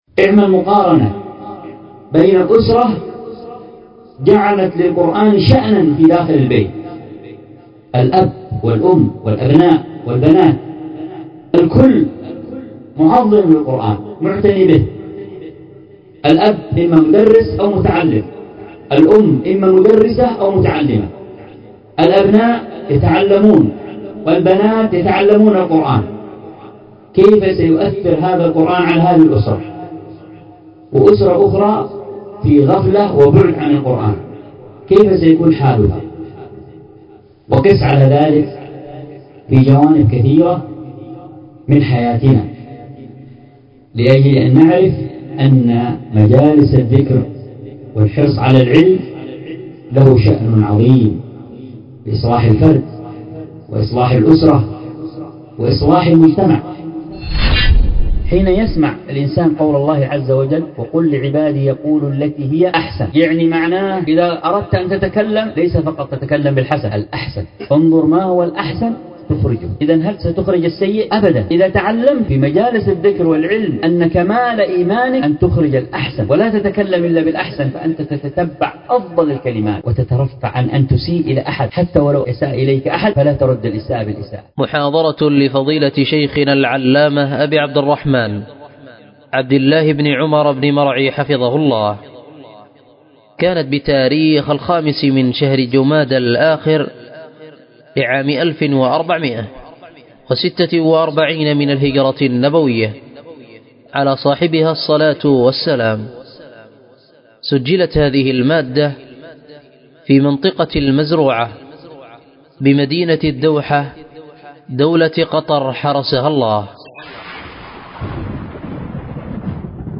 كتاب العلم وفضله وما يتعلق به 12 تحميل الدرس في كتاب العلم وفضله وما يتعلق به 12، الدرس الثاني عشر:من( وأما المعلم فعليه وظائف أيضاً ...